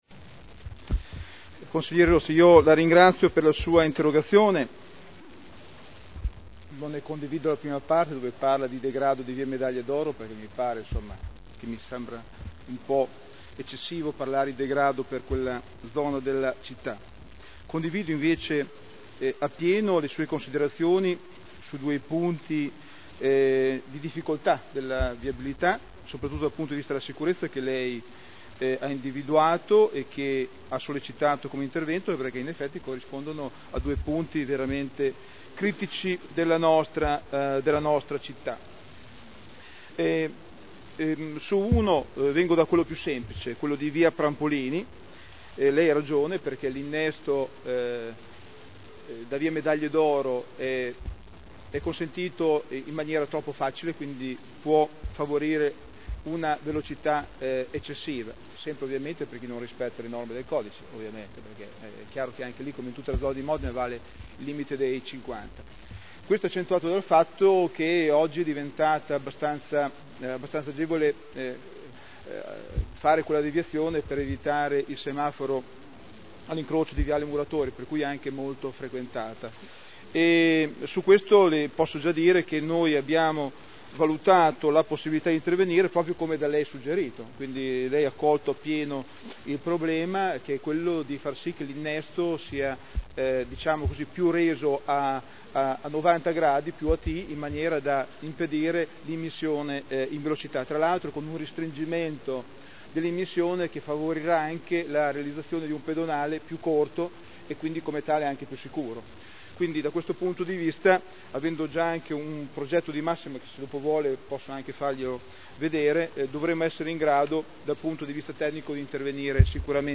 Seduta del 14/12/2009. Viabilità zona Via Medaglie d’oro, via Prampolini, via Carlo Sigonio